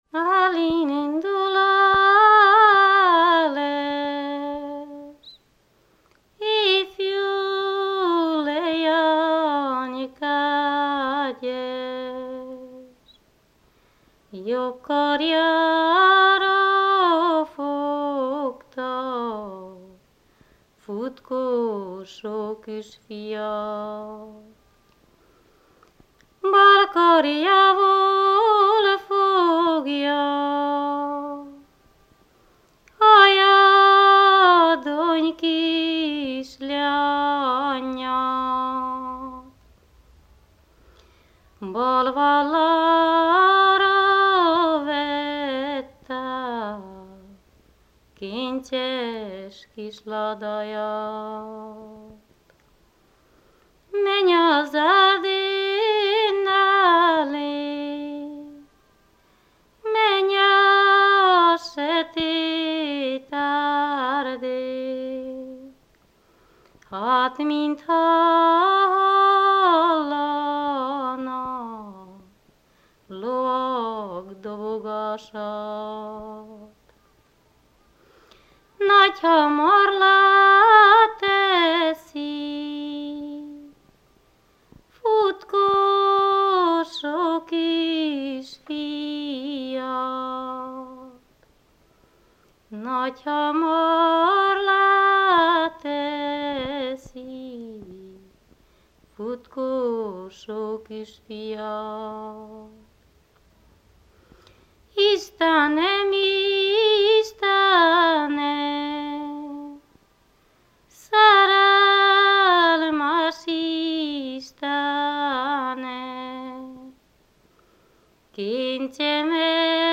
Előadásmód: ének Műfaj: ballada
Település: Gerlén Tájegység: Moldva (Moldva és Bukovina)